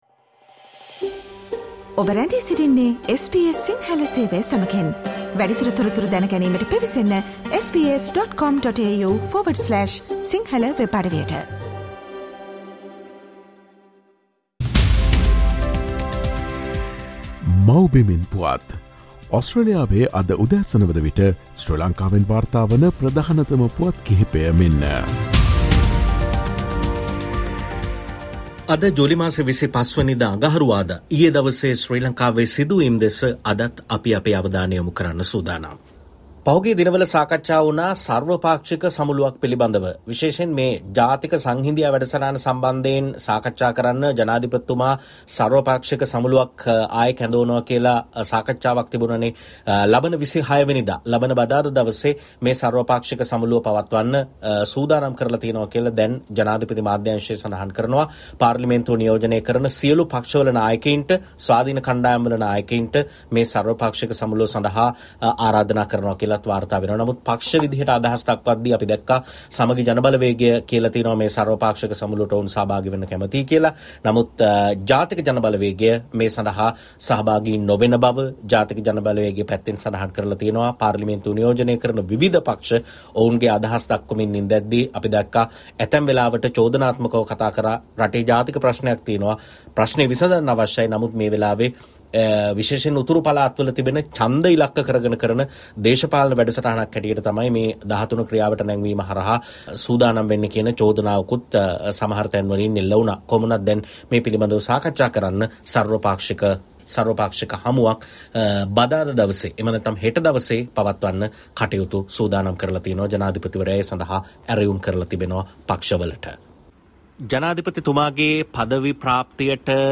SL News report July 25: While the S.J.B. goes to the President's All-Party Conference, the "N.P.P." says no